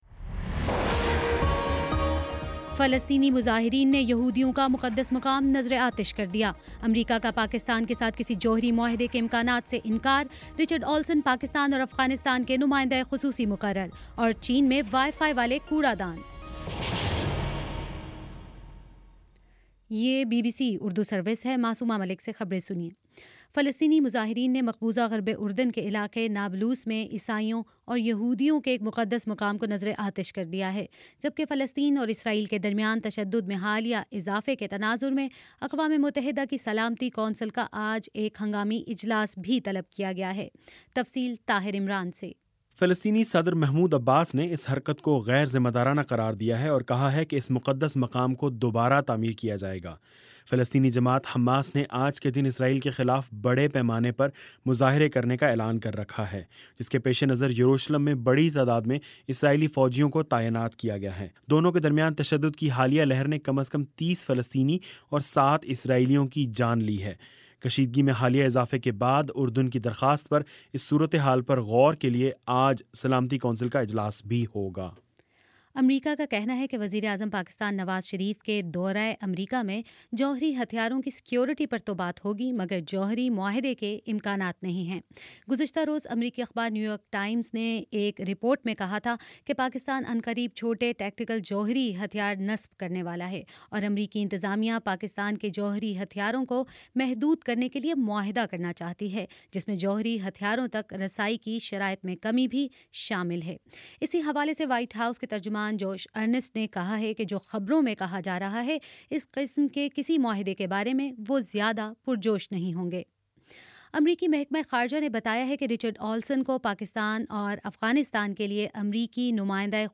اکتوبر 16 : شام چھ بجے کا نیوز بُلیٹن